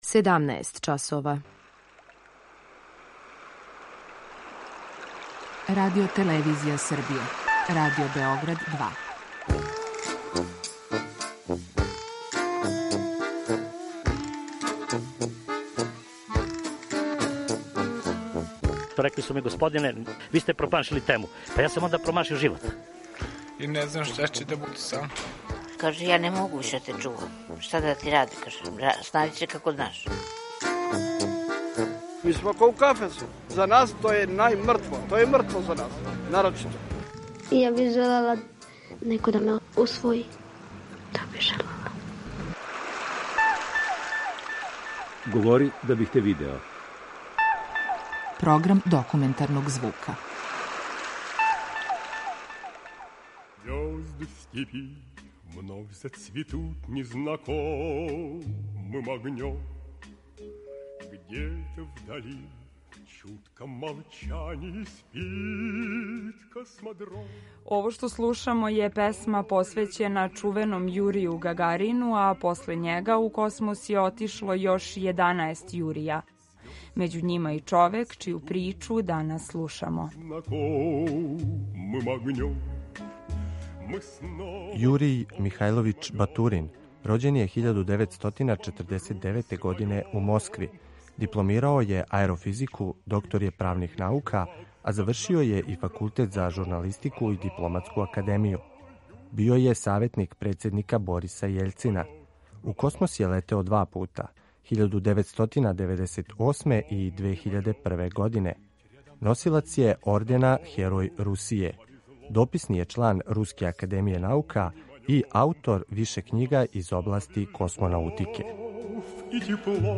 Документарни програм
Чућете и специјалне снимке из самог космоса, које је Радио-телевизији Србије уступио Јуриј Батурин.